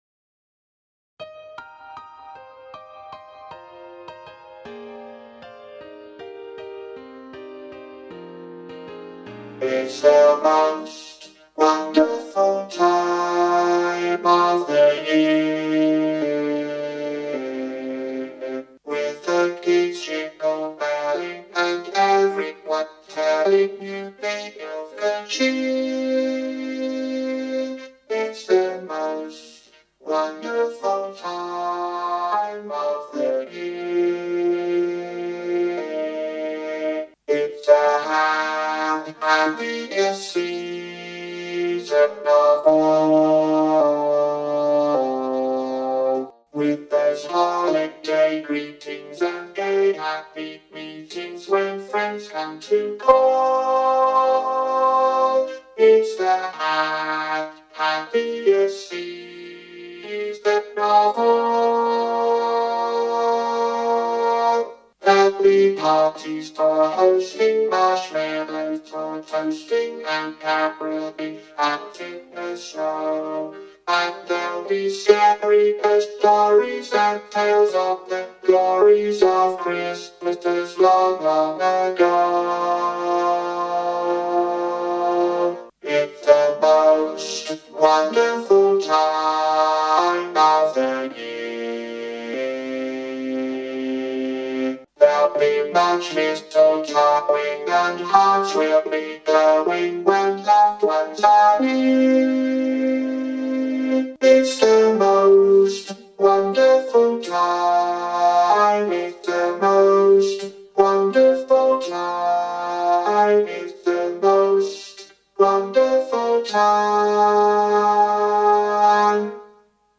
Its-The-Most-Wonderful-Time-Soprano2.mp3